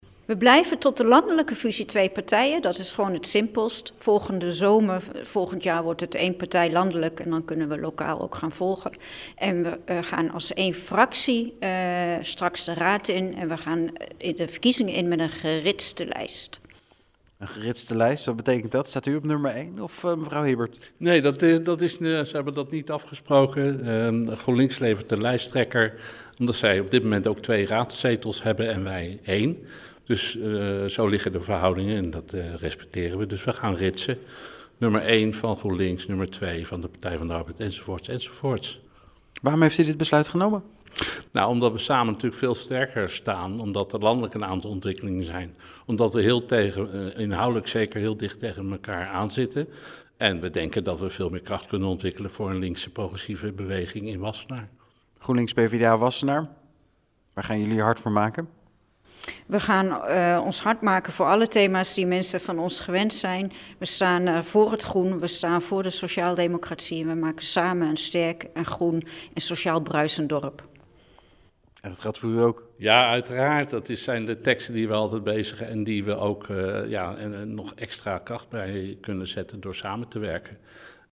Fractievoorzitters Hibbert en Van Smirren over het samen meedoen aan de gemeenteraadsverkiezingen in Wassenaar: